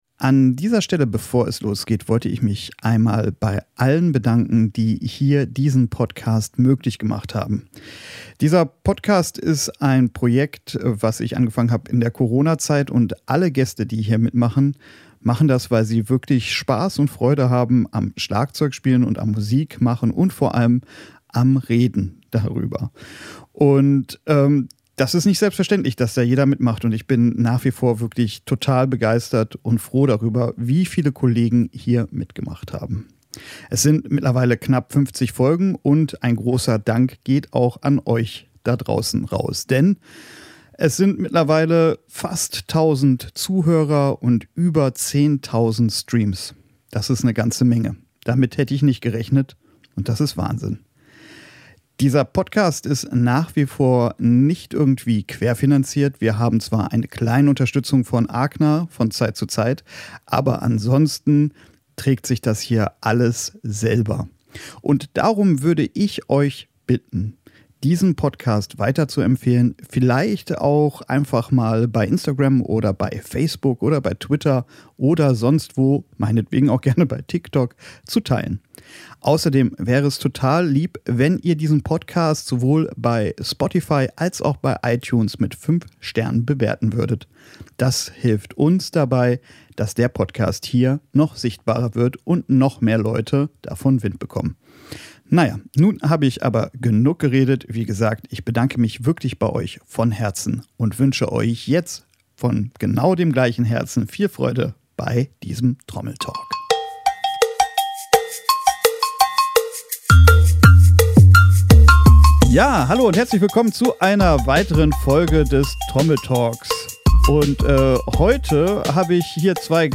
Und so haben wir uns bei einem Bierchen zusammengesetzt und gemeinsam philosophiert, warum Gaffa Tape auf Cowbells erst gut klingt, wenn es zerbröselt, was das Geheimnis hinter Steve Gadds sagenhaften Cowbell Grooves ist und wer die größten Glocken von Köln hat.